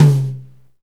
Index of /90_sSampleCDs/Northstar - Drumscapes Roland/KIT_Hip-Hop Kits/KIT_Rap Kit 3 x
TOM H H HI0M.wav